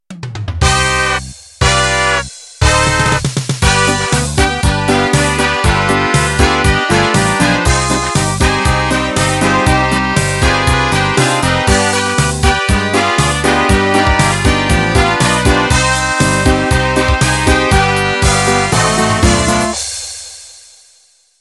First in a pair of contemporary, Pop Polkas.